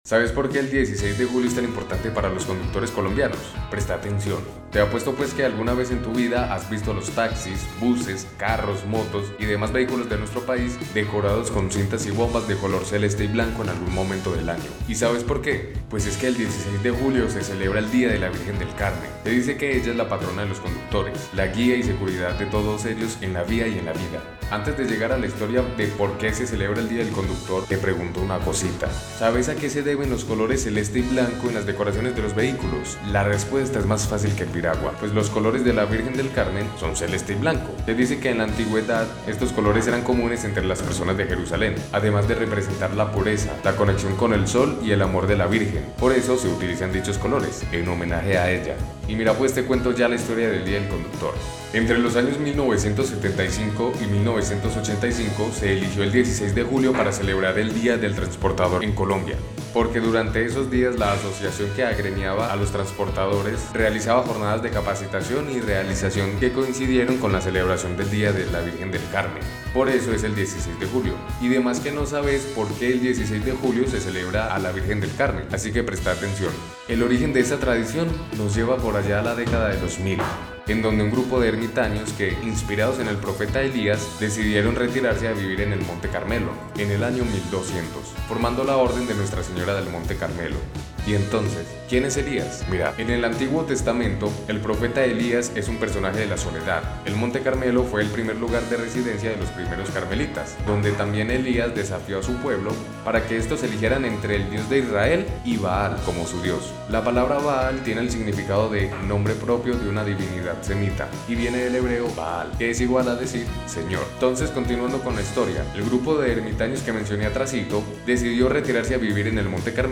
No te preocupés, acá te lo leemos.
Narracion-del-origen-del-dia-del-conductor.mp3